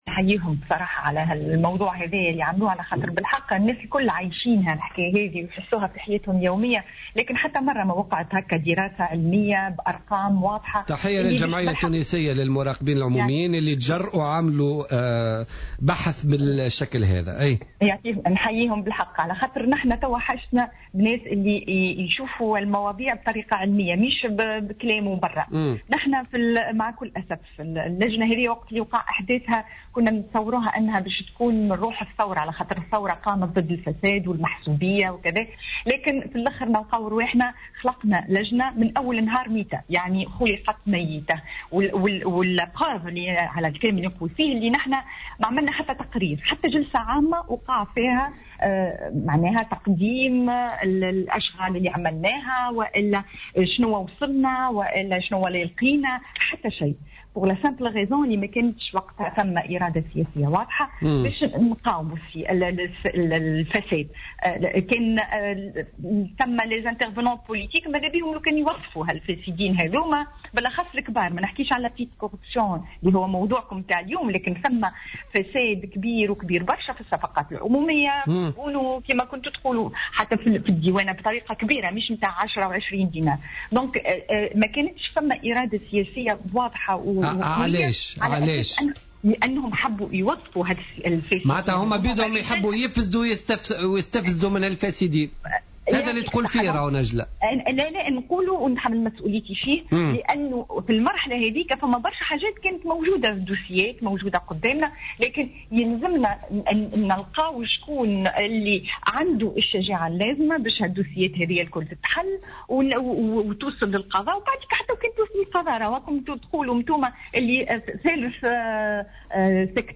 أكدت النائب السابق بالمجلس التأسيسي نجلاء بوريال في مداخلة لها في برنامج بوليتيكا اليوم الخميس 5 مارس 2015 أن لجنة مكافحة الفساد في المجلس التأسيسي ولدت ميتة وكان من المتوقع حين تم احداثها أن تكون مستمدة من روح الثورة ولكنها خلقت منذ أول يوم ميتة وكانت لجنة فاشلة لم تأت بأية نتيجة تذكر لمكافحة الفساد.